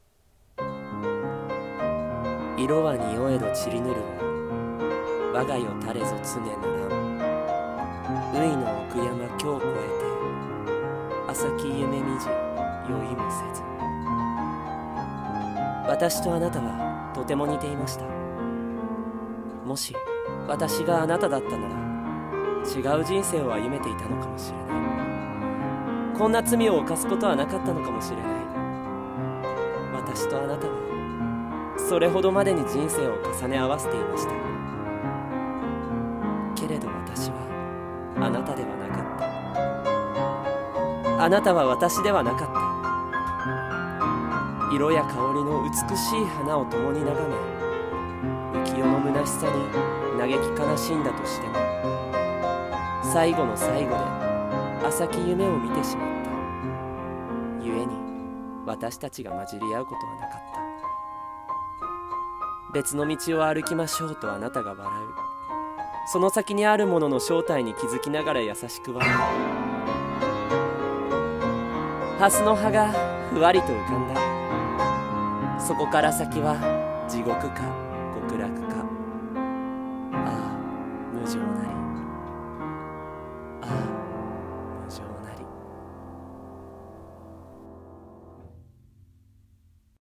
一人声劇】いろはにほへと